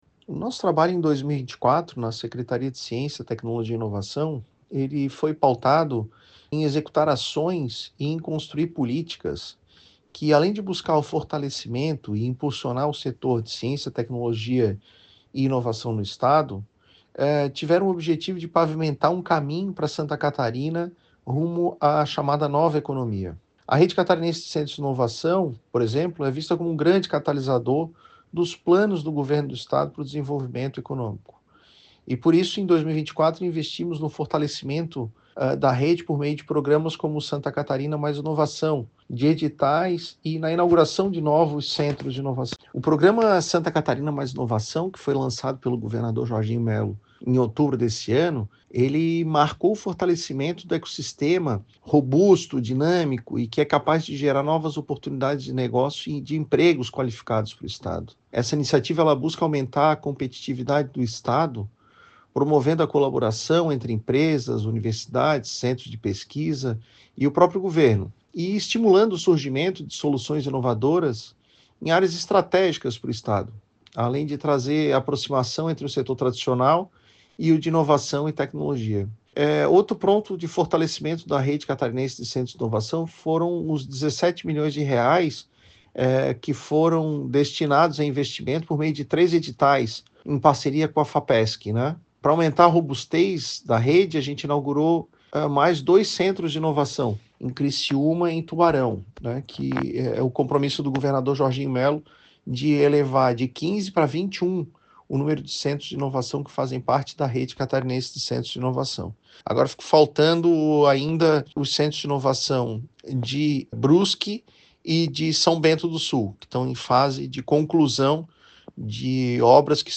RETROSPECTIVA-SECOM-2024-Secretario-da-SCTI-1.mp3